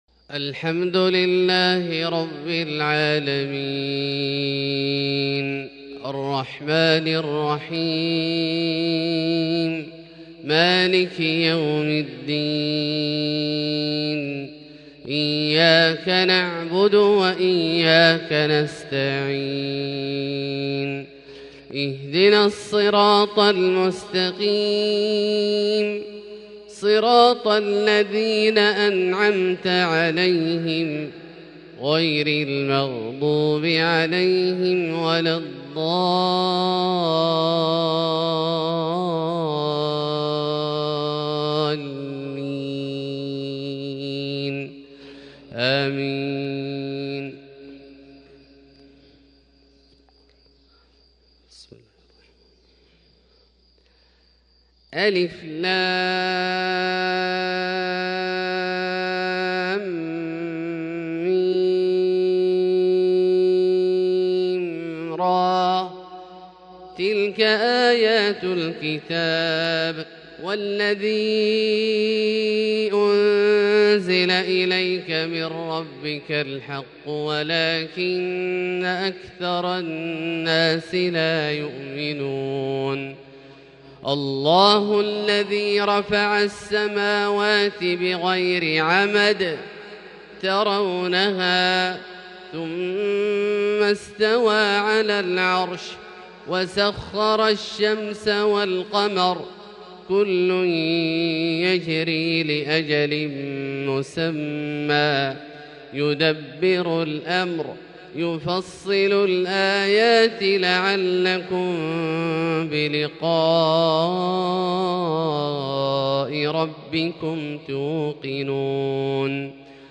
فجر الأربعاء 5-7-1442هـ من سورة الرعد Fajr Prayar from Surah Ar-Ra'd | 17/2/2021 > 1442 🕋 > الفروض - تلاوات الحرمين